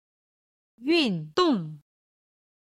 运动　(yùn dòng)　運動する